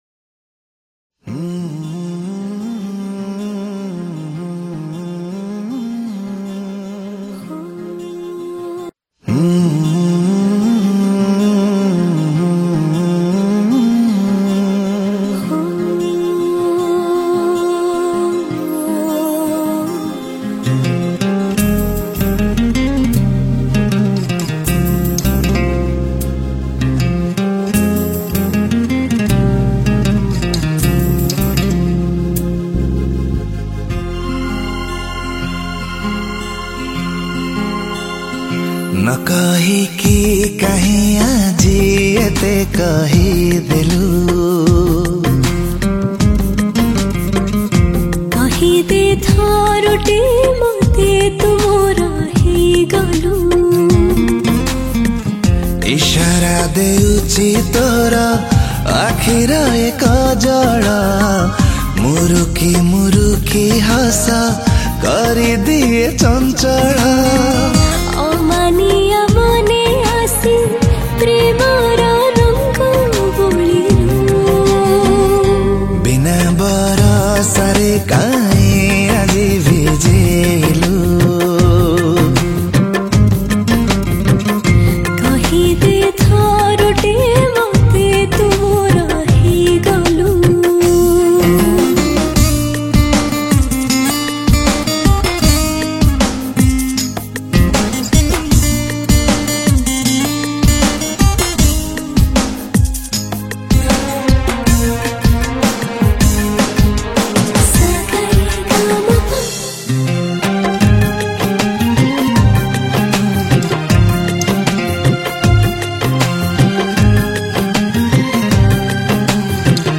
Flute
Guitar